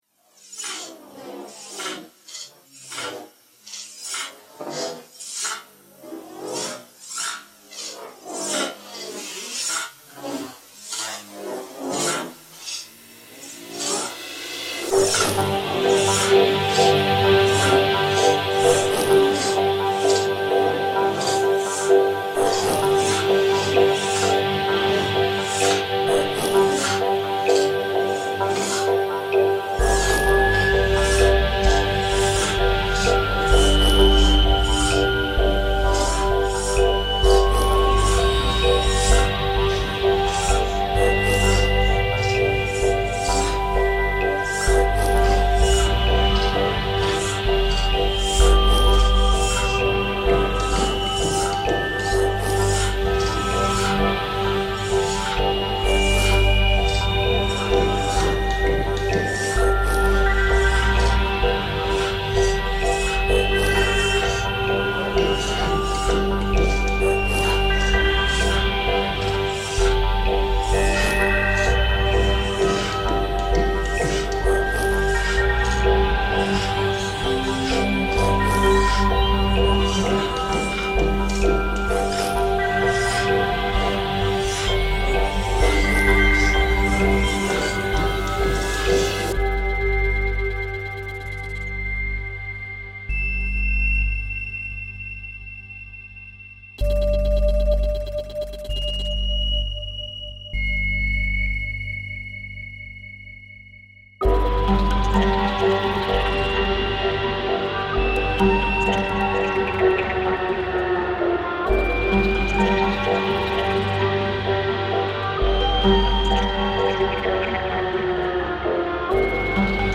I wanted to create something that would feel odd and a bit disorienting to the listener. I got started and spent weeks reinterpreting the percussive music of the earth bow, a single-stringed musical instrument.